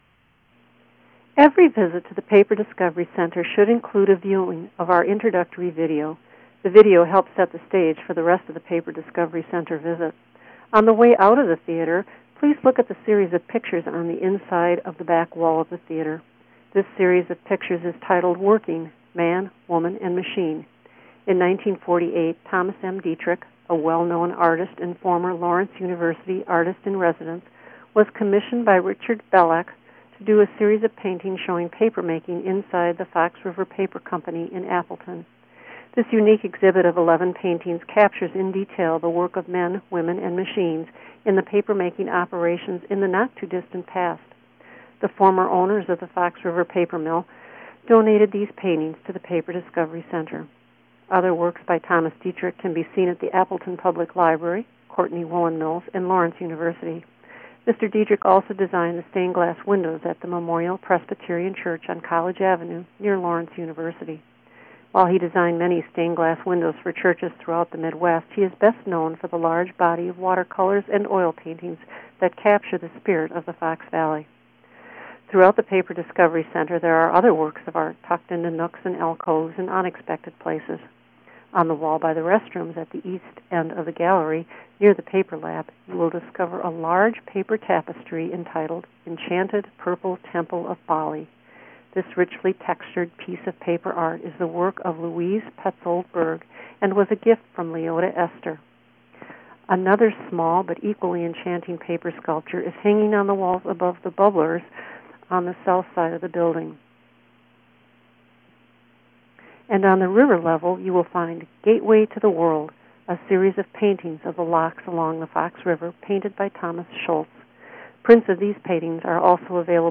Audio Tour